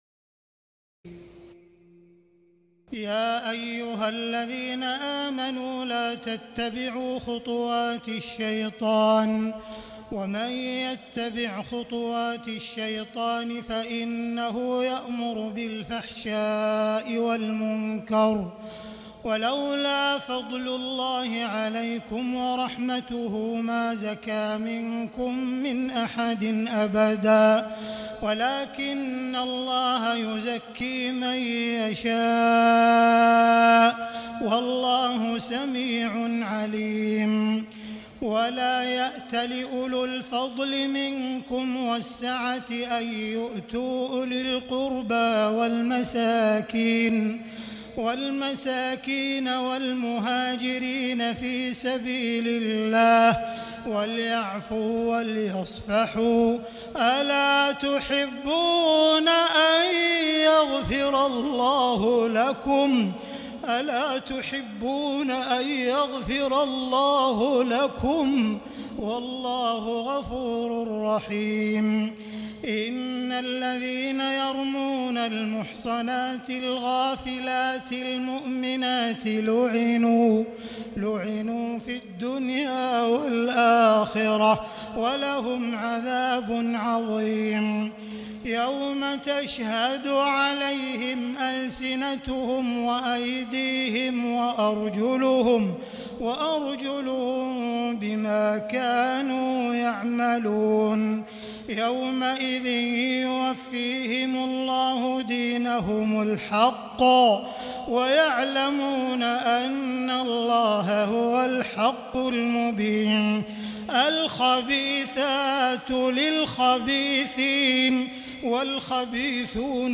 تراويح الليلة السابعة عشر رمضان 1439هـ من سورتي النور (21-64) و الفرقان (1-20) Taraweeh 17 st night Ramadan 1439H from Surah An-Noor and Al-Furqaan > تراويح الحرم المكي عام 1439 🕋 > التراويح - تلاوات الحرمين